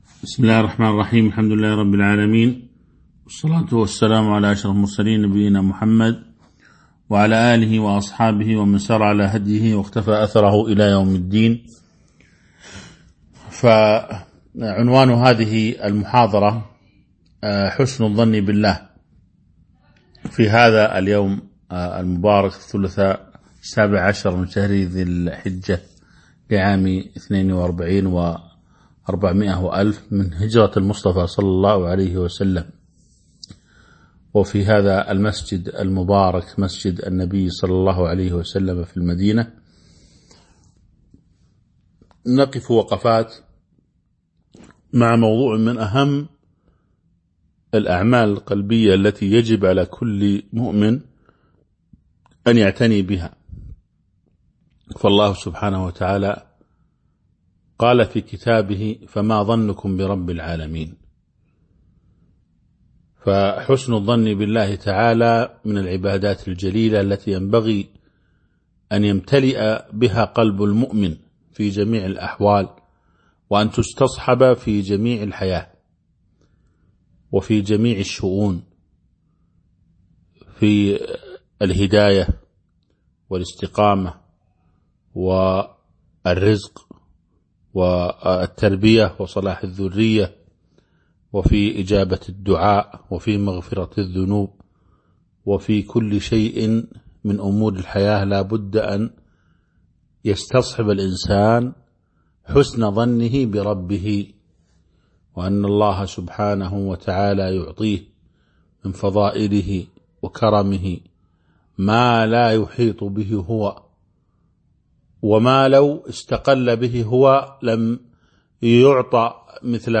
تاريخ النشر ١٧ ذو الحجة ١٤٤٢ هـ المكان: المسجد النبوي الشيخ